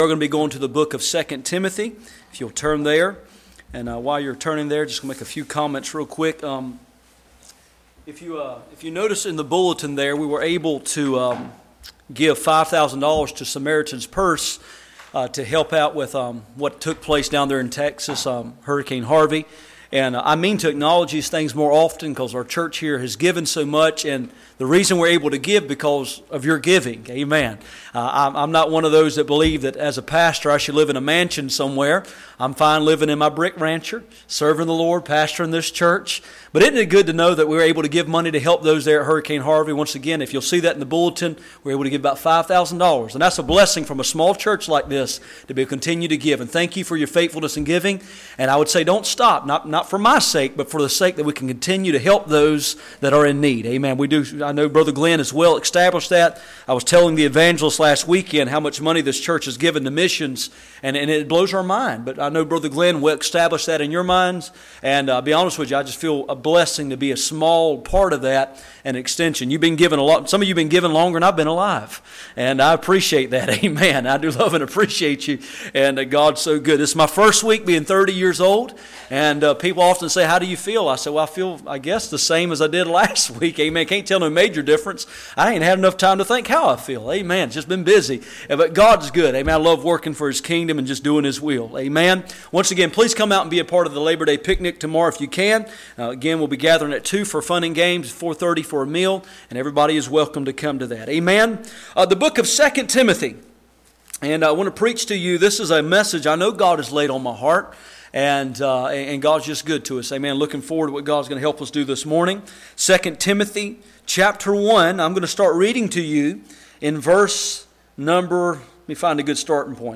Ezra 8:22-29 Service Type: Sunday Morning %todo_render% « The result of rejecting revival.